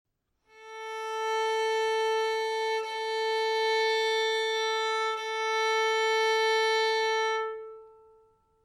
Stimmton für die 2. Saite (a'=443 Hz)
stimmton_a.ogg